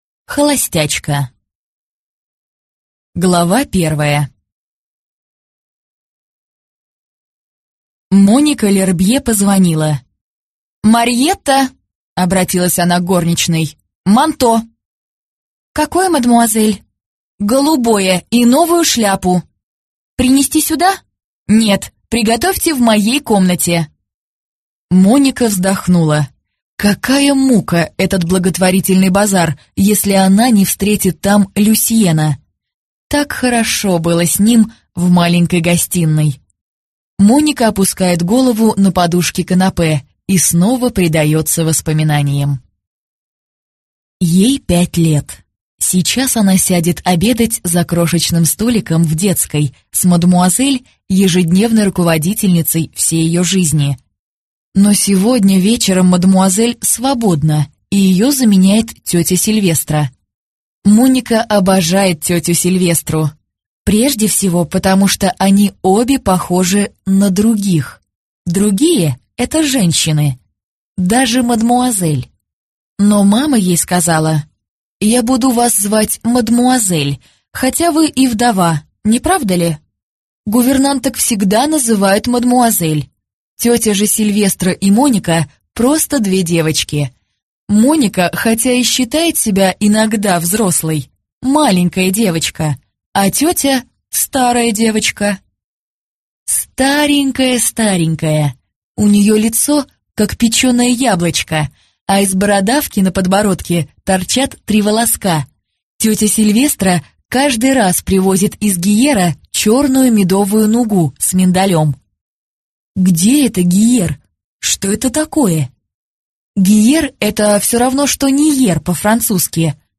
Аудиокнига Холостячка | Библиотека аудиокниг